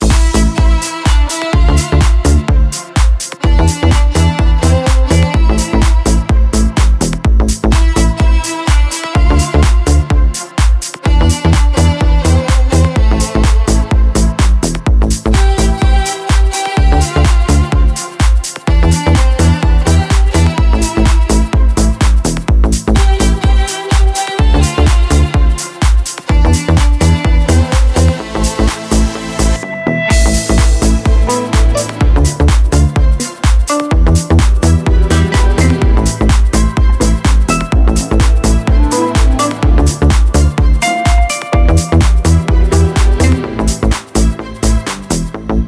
громкие
remix
deep house
dance
Electronic
Club House
без слов
скрипка
Фрагмент классного ремикса в стиле deep house.